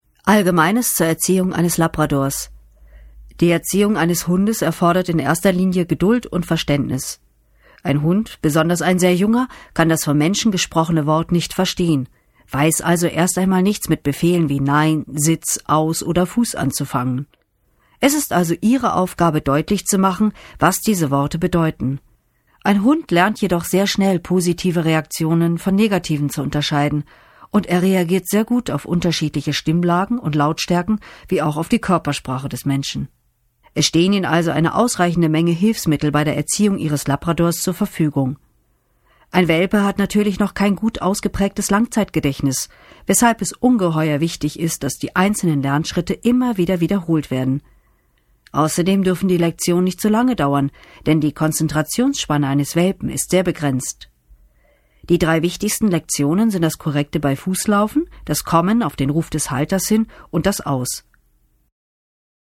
Schauspielerin, Sprecherin
Sprechprobe: eLearning (Muttersprache):